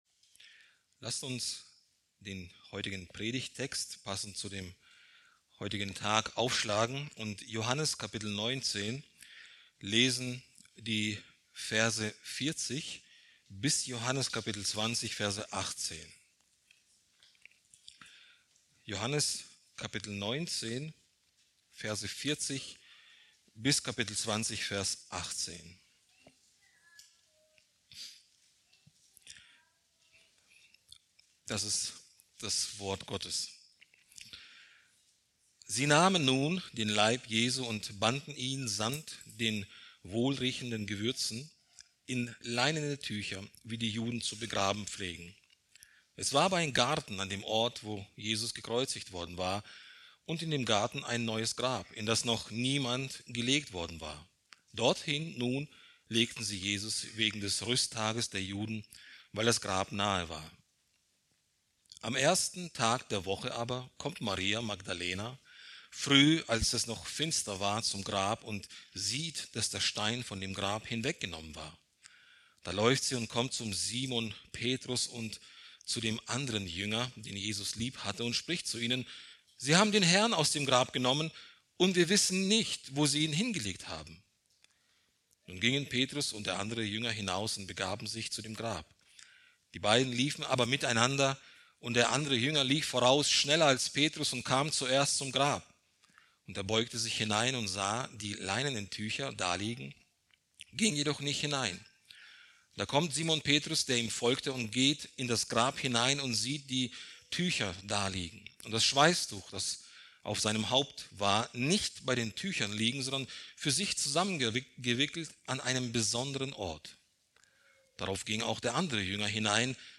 Predigt aus der Serie: "Evangelium nach Markus"